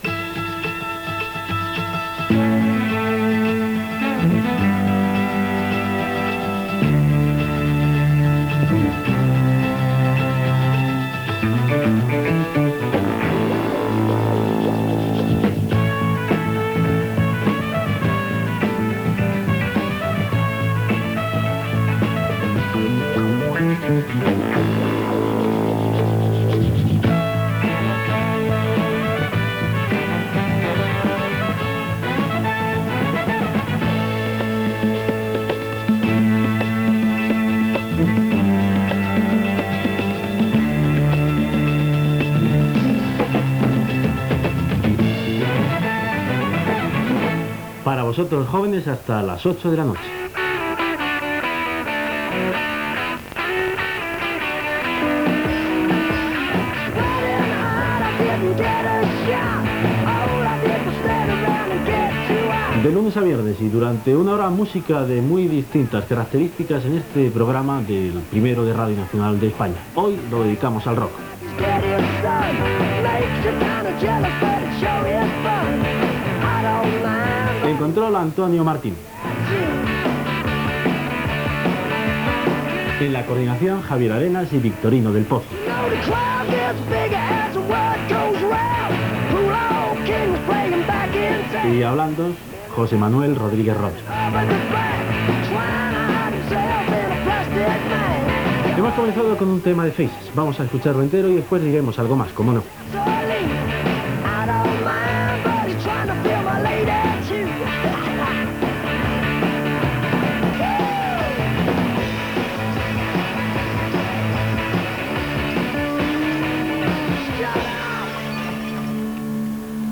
Indicatiu musical d'estil "soul" de l'emissora. Presentació del programa que en aquesta ocasió està relacionada amb música rock. Menció de l'equip del programa i sona la peça "Pool Hall Richard" de The Faces. Acabament de la peça i presentació de la següent que s'anomena "White Honey" de Graham Parker & The Rumour.
Musical